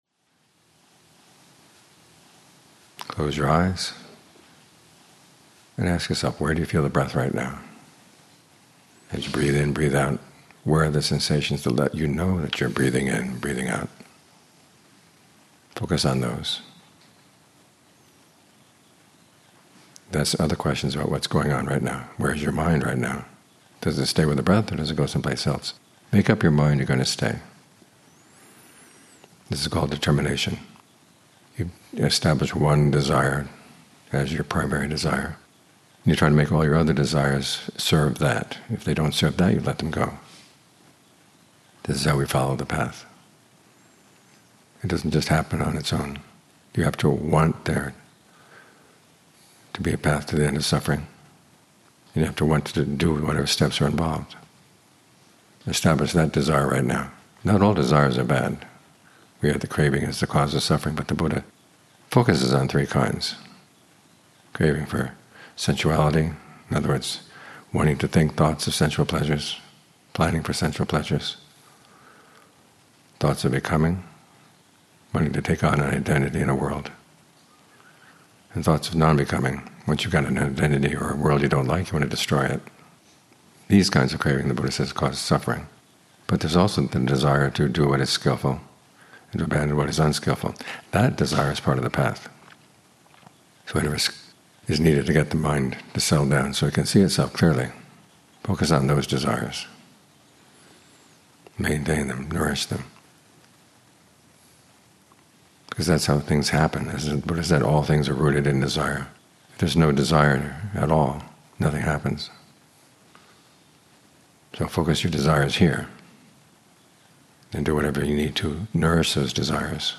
Morning Talks